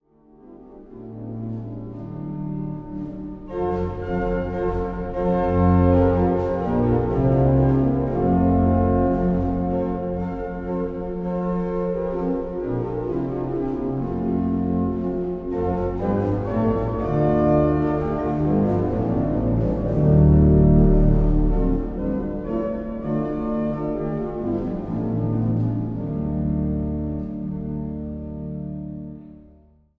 Die Königin der Instrumente in Bildern und Tönen